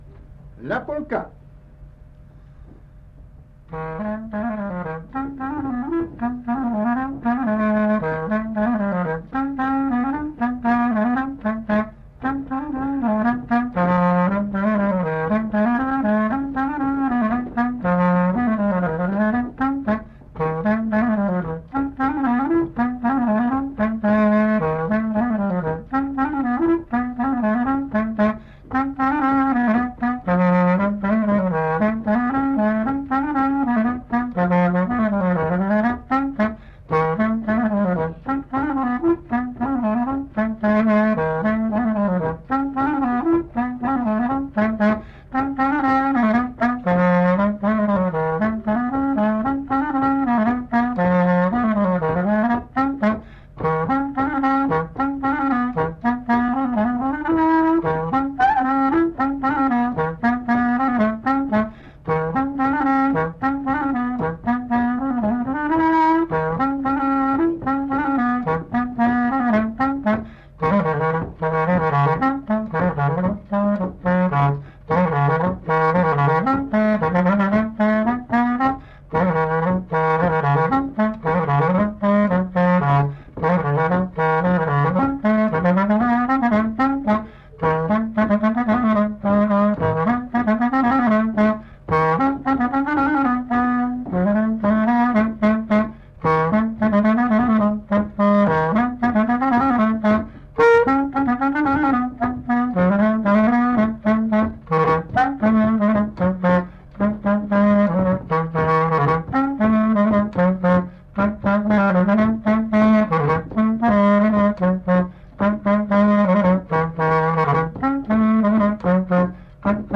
Aire culturelle : Couserans
Département : Ariège
Genre : morceau instrumental
Instrument de musique : clarinette
Danse : polka